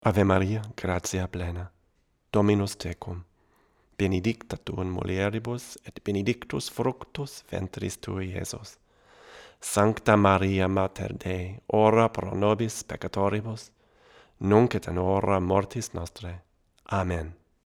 Here are 33 quick, 1-take MP3s using this UM70 and M70 in a large room going into a Audient Black mic pre, into a Sony PCM D1 flash recorder, with MP3s made from Logic. These tracks are just straight signal with no additional EQ, compresson or effects:
VOICE OVER (M70):